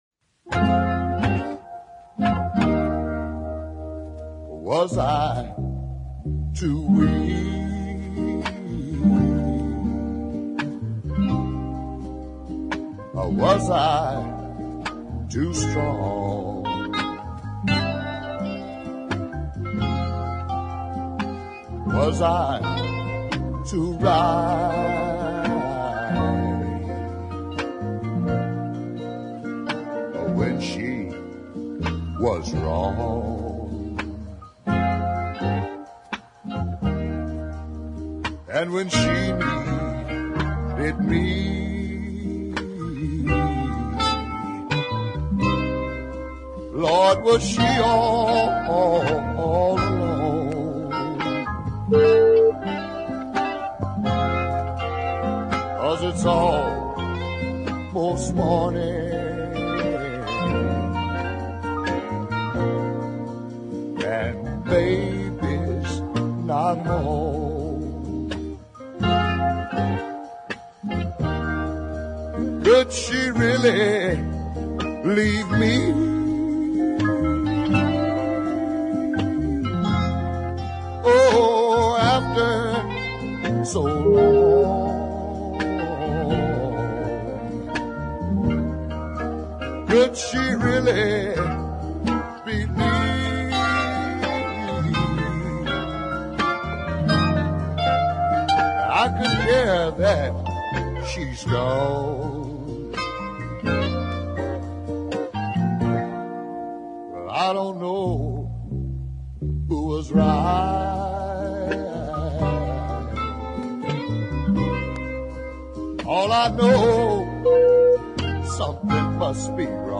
a soul track of power and emotional weight